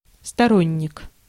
Ääntäminen
Translitterointi: storonnik.